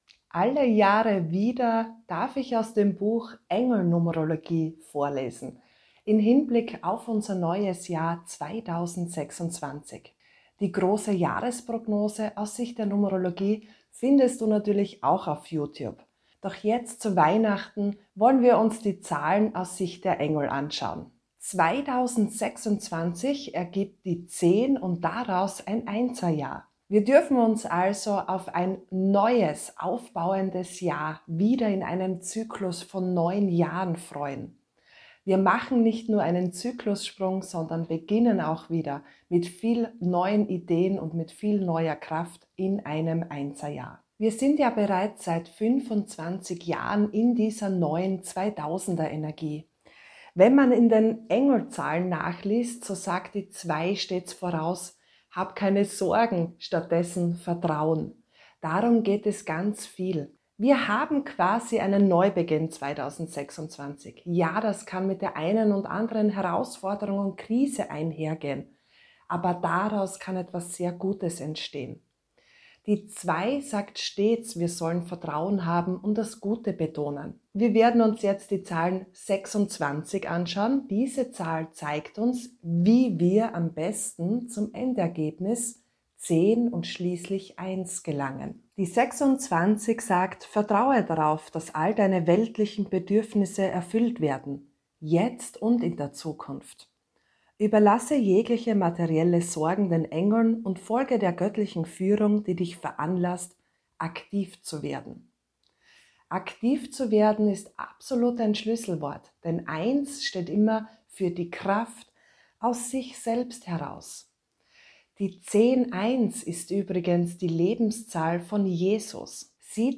Alle Jahre wieder darf ich zu Weihnachten aus dem Buch: Die